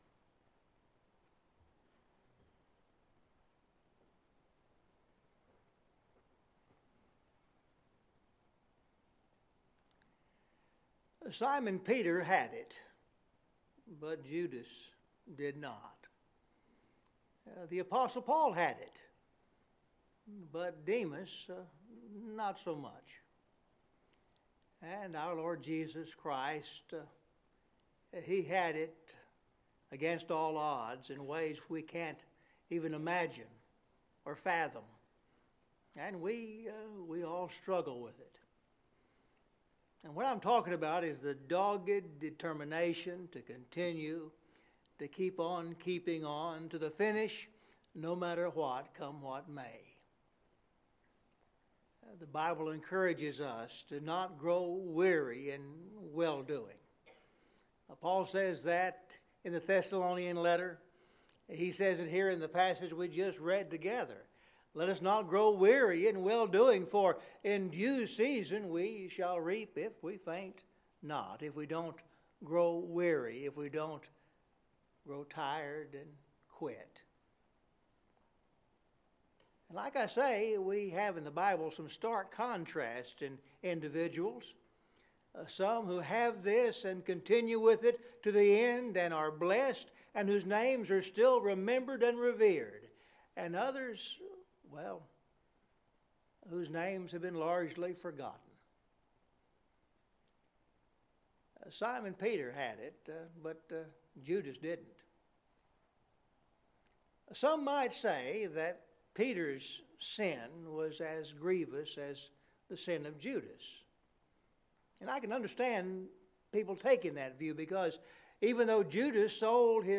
Scripture Reading – Galatians 6:7-10